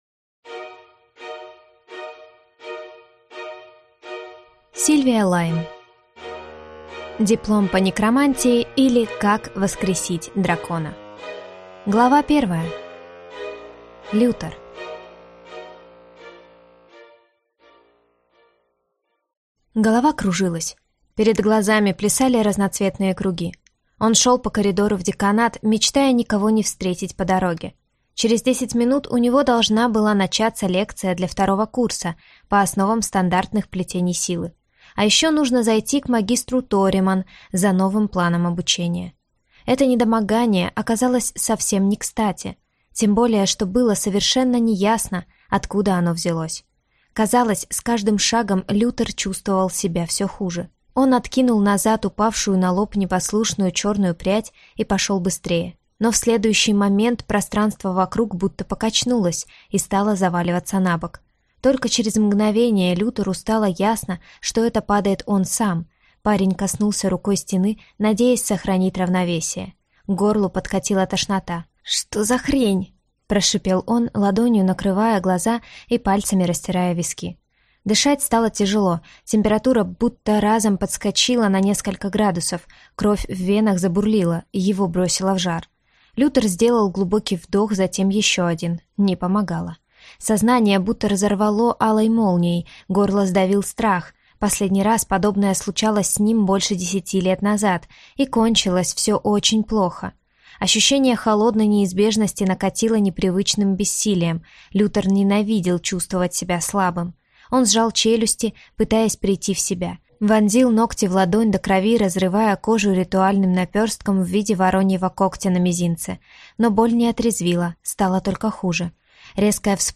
Аудиокнига Диплом по некромантии, или Как воскресить дракона | Библиотека аудиокниг